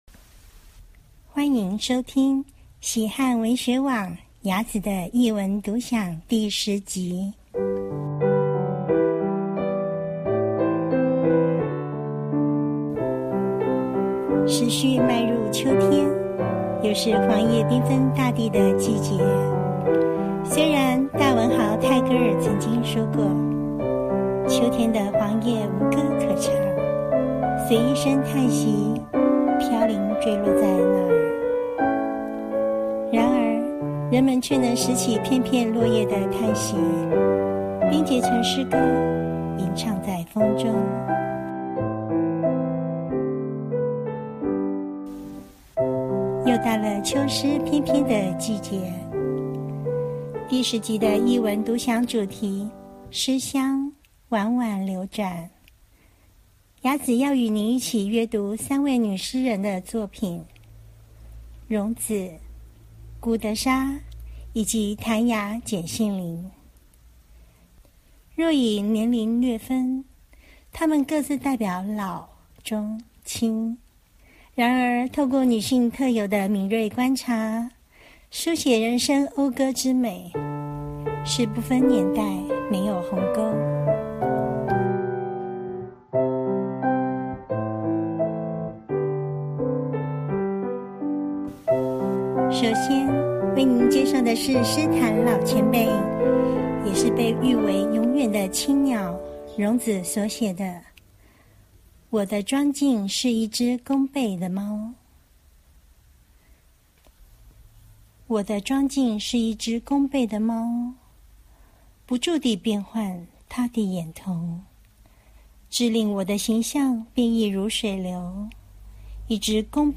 音樂演奏